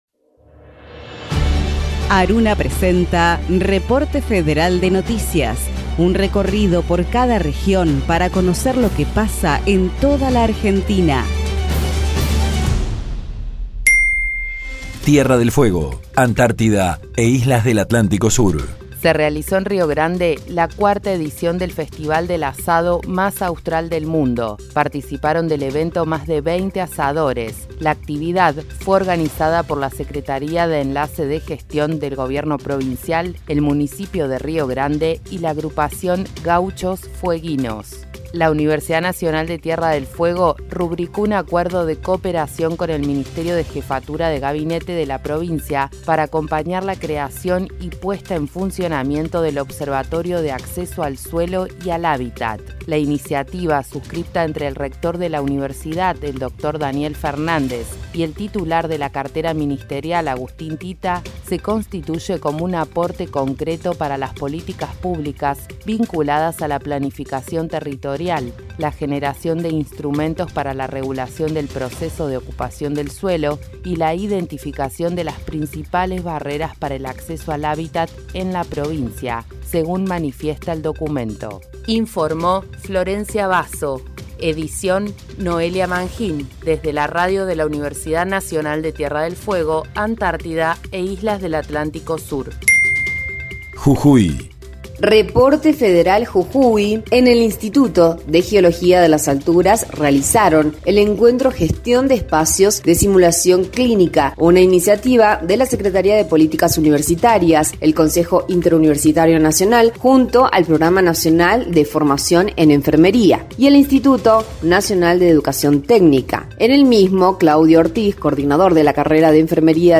Reporte Federal Texto de la nota: Radio UNDAV - Reporte Federal de noticias Producción colaborativa de ARUNA Las emisoras de universidades nacionales que integran la Asociación de Radios Universitarias Nacionales Argentinas (ARUNA) emiten un informe diario destinado a brindar información federal con la agenda periodística más destacada e importante del día. Un programa que contiene dos noticias por cada radio participante, una noticia institucional de las universidades nacionales y otra local o provincial de interés social, con testimonios de las y los protagonistas locales.